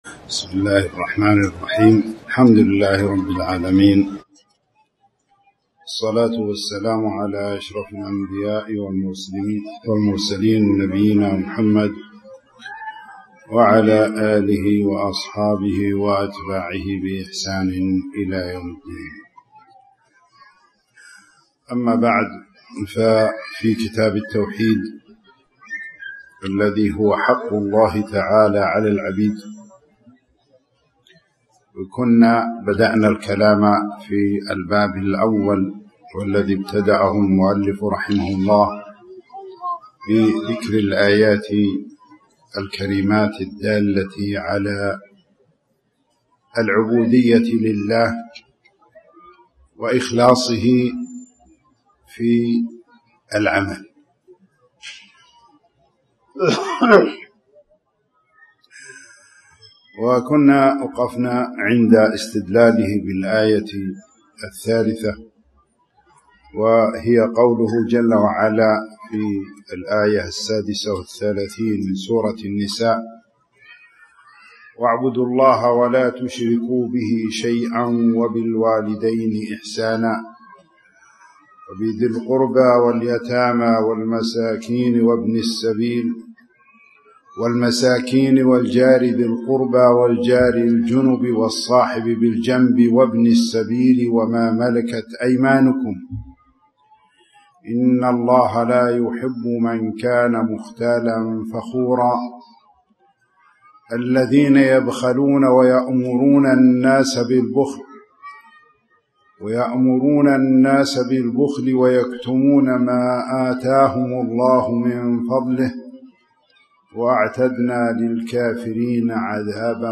تاريخ النشر ١١ محرم ١٤٣٩ هـ المكان: المسجد الحرام الشيخ